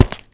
Land1.wav